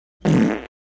Звуки пердежа, пукания
Человек пукает третий раз